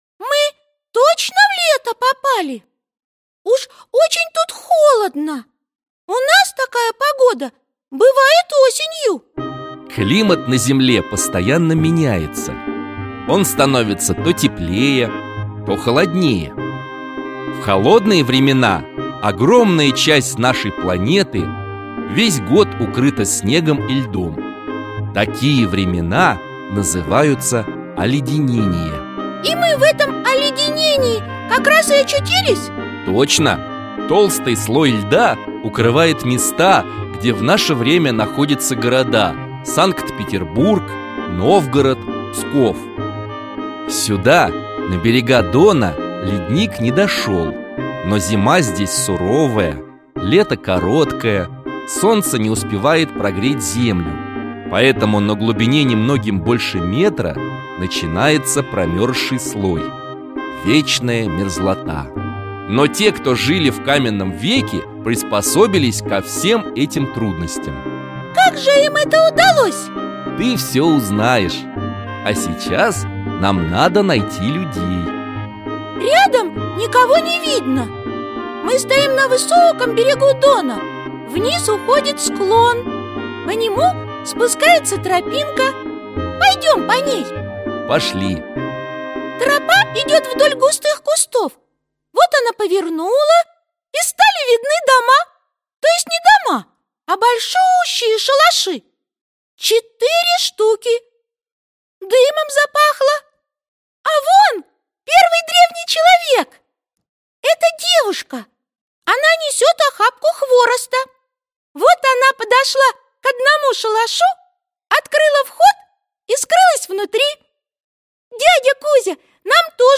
Аудиокнига Путешествие в Каменный век | Библиотека аудиокниг